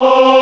classical_choir_voice_ahhs.wav